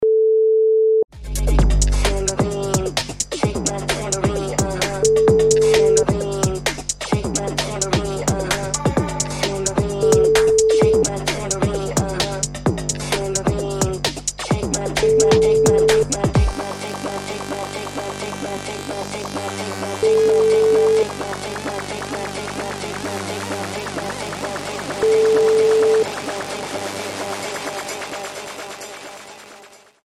Easy Listening Dance